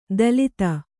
♪ dalita